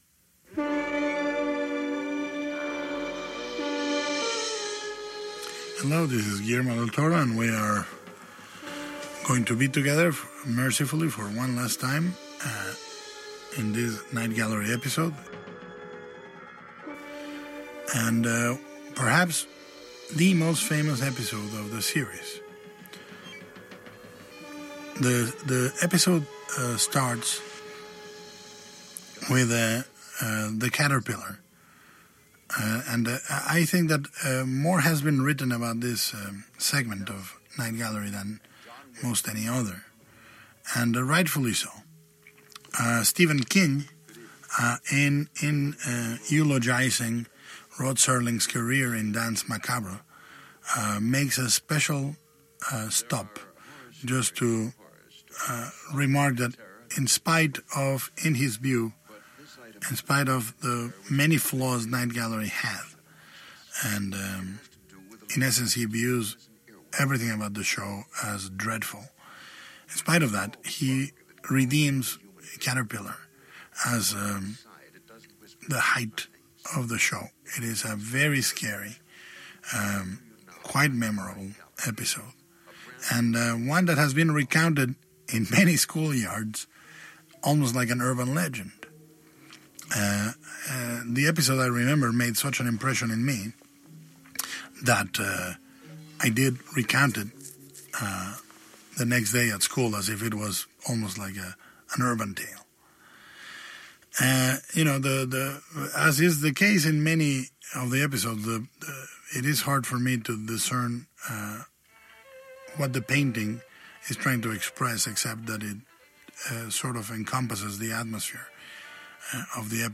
Rod Serling introducing the Night Gallery episode, “The Caterpillar”